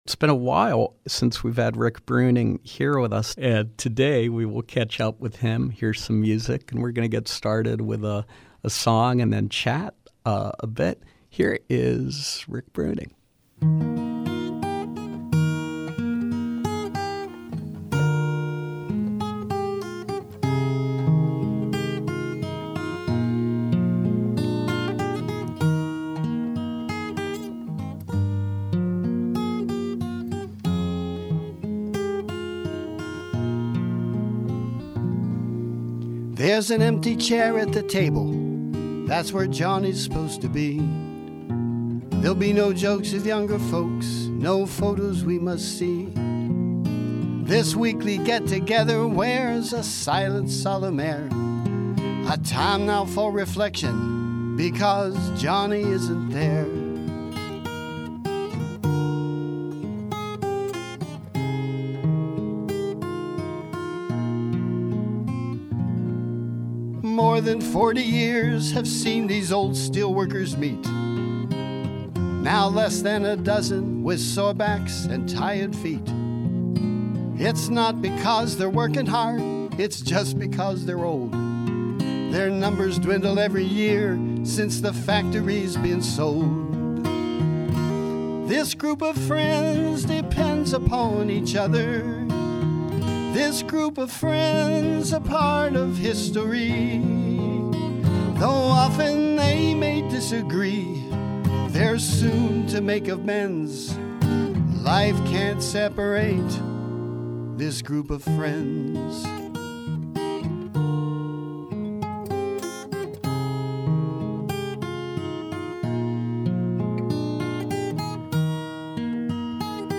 Live music and conversation with singer/songwriter